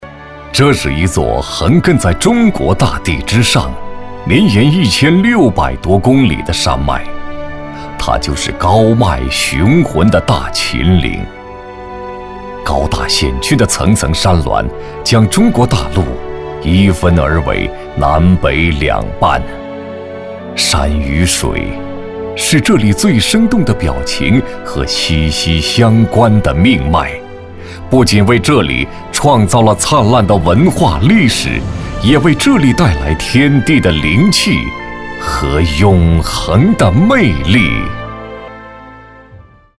风光片男196号（大秦岭）
深情缓慢 旅游风光
大气沉稳男音，声线中年。擅长专题抗战、风光解说、人物讲述等题材。